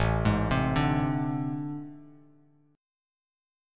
AmM7bb5 chord